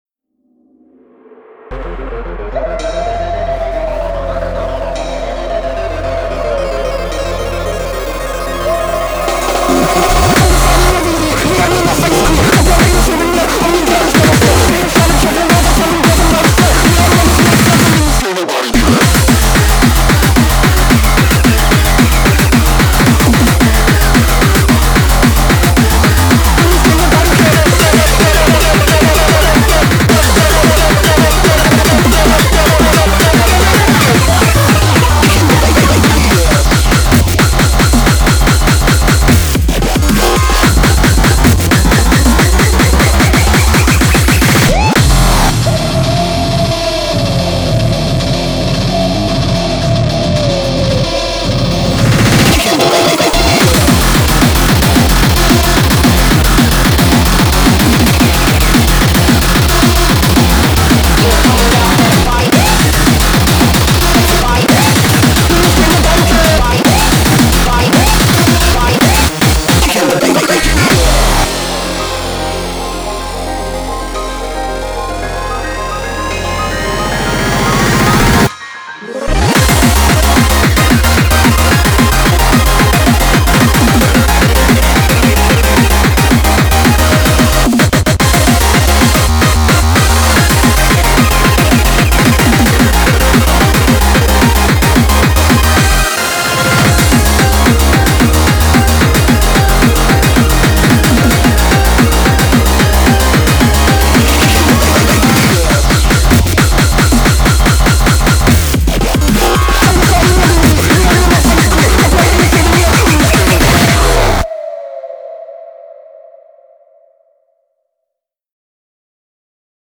BPM111-222
Audio QualityCut From Video
What mind-numbing speedcore they make, eh?